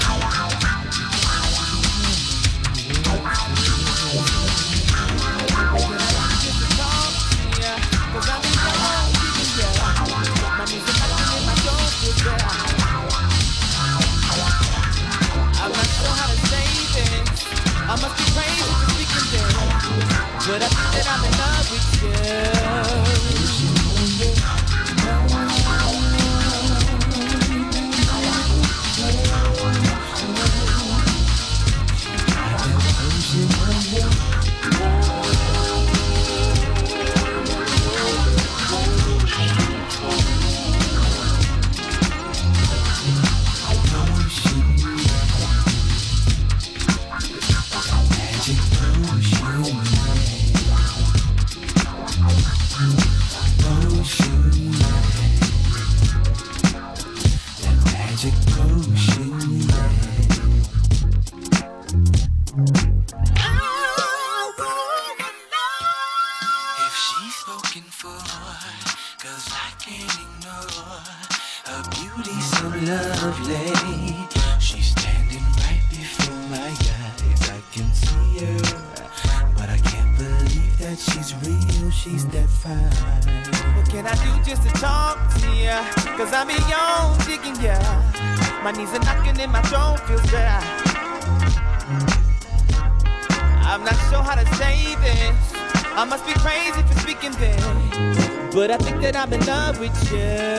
Exclusive mix CD by one of Detroit's finest DJ's...
Disco House Soul